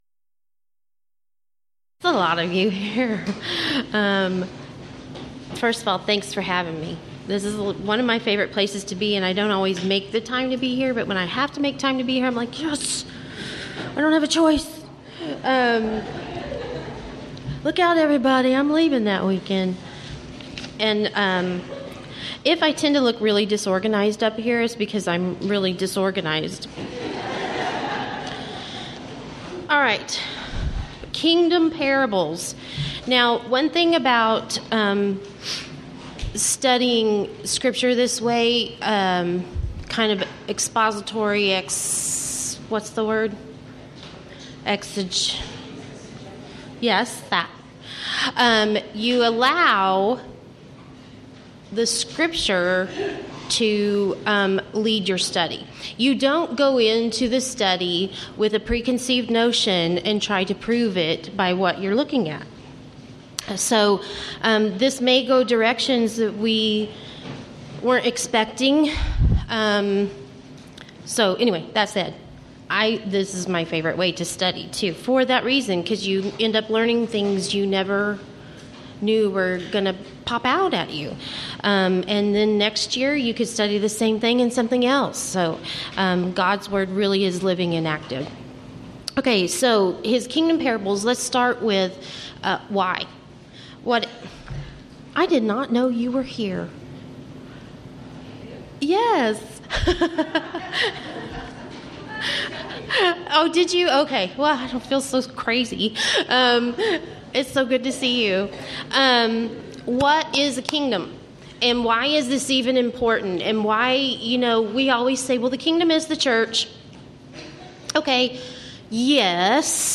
Event: 9th Annual Texas Ladies in Christ Retreat
Ladies Sessions